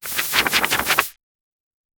Suitable for a lively and whimsical animation 0:08 Generate a short positive sound effect, that represents sending a letter. Start with a soft paper rustle or pen stroke, followed by a gentle "whoosh" and a bright chime. It should feel cheerful and rewarding, suitable for a digital game. 0:02 simple background sound effect happy mood 0:08
generate-a-short-positive-x4o6fttg.wav